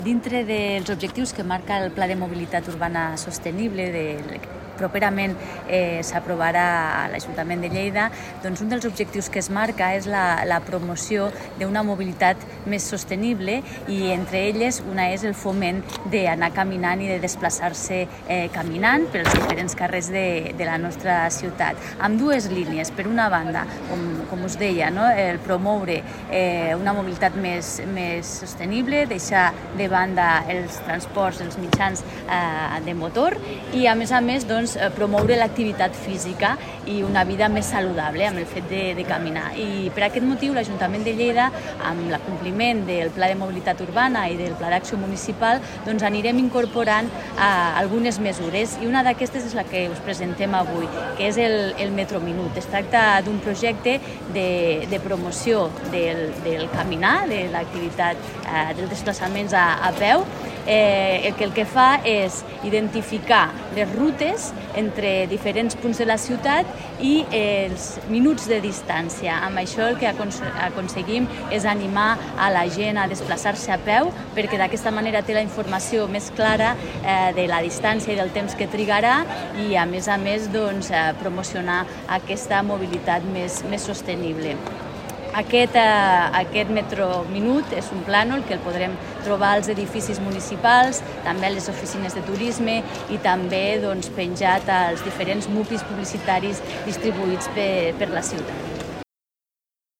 Tall de veu de la 3a tinenta d'alcalde i regidora de Seguretat, Mobilitat i Civisme, Cristina Morón, sobre el plànol Metrominut (1.4 MB) Plànol Metrominut Lleida (1.1 MB) Flyer plànol Metrominut Lleida (1.2 MB)